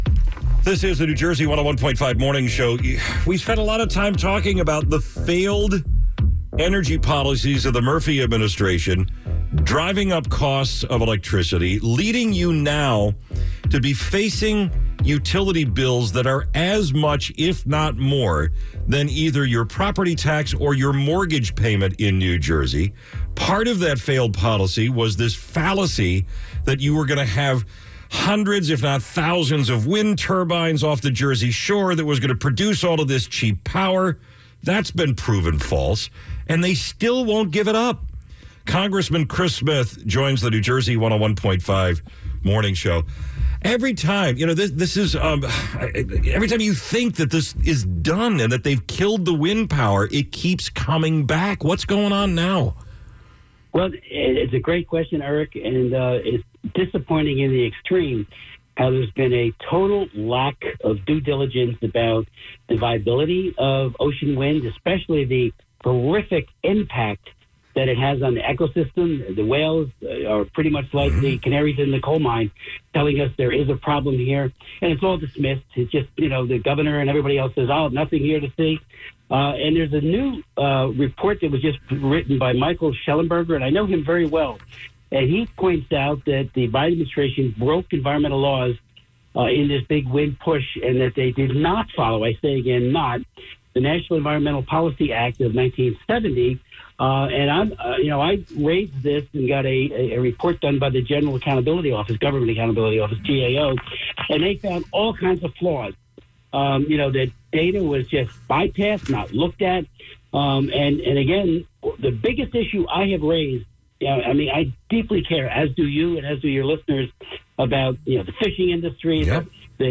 Smith guests on New Jersey 101.5Congressman Smith continues to voice his opposition to offshore wind towers